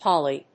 /pάli(米国英語), pˈɔli(英国英語)/